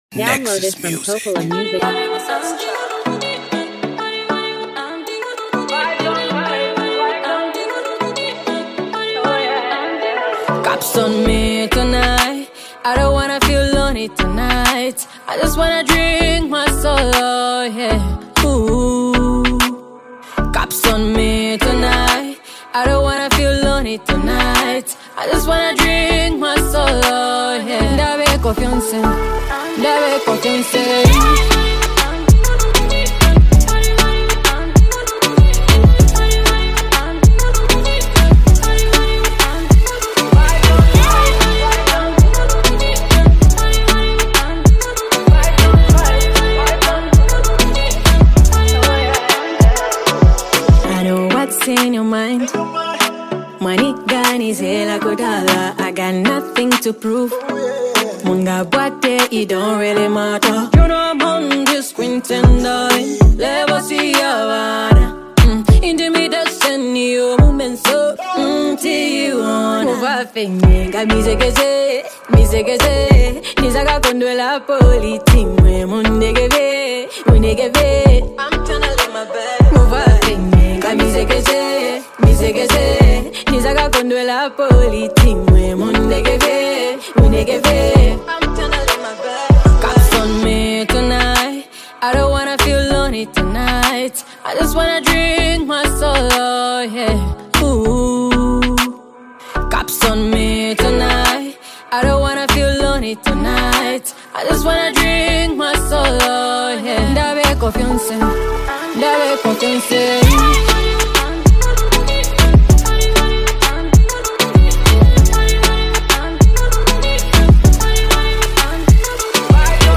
smash hit dance track